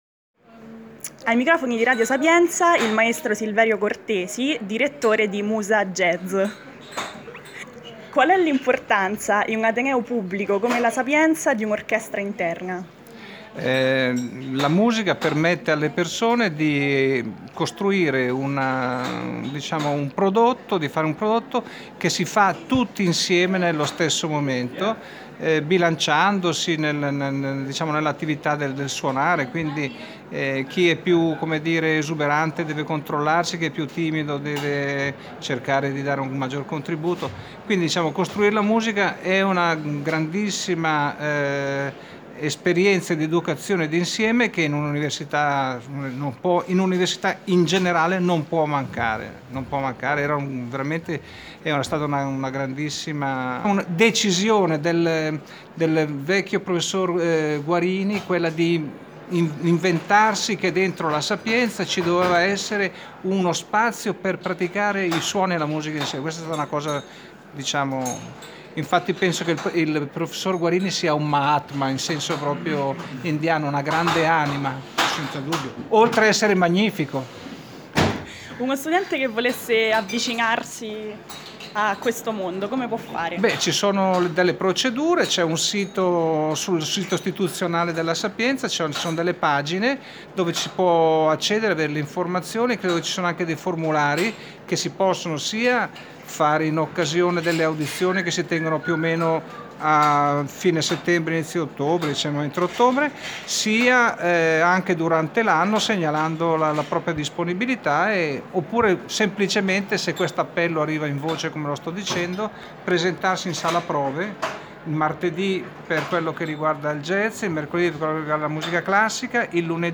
Martedì 19 dicembre, presso l’Aula magna del Rettorato, il Rettore Eugenio Gaudio e il Direttore...